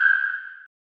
sonar4.mp3